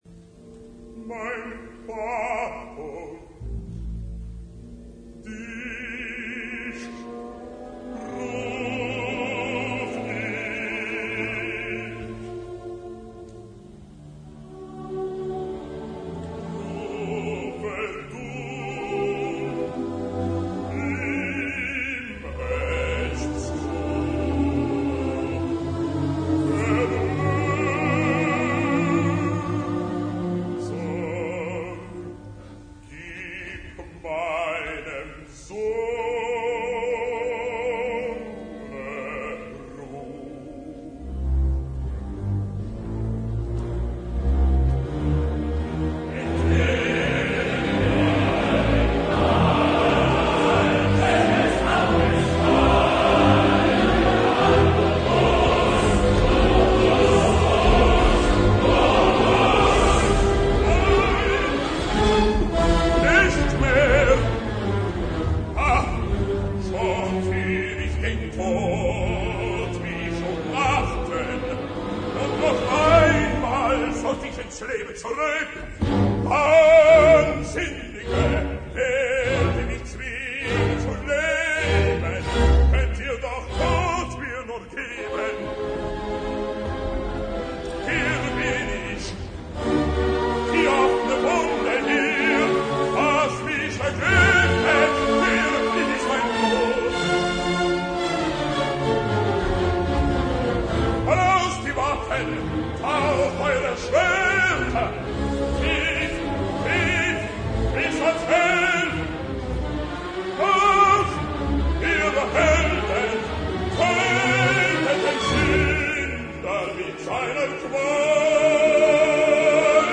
Festival sagrado de consagración escénica en tres actos.
Actuación del Coro y de la Orquesta del Teatro de los Festivales de Bayreuth, bajo la conducción de CLEMENS KRAUSS.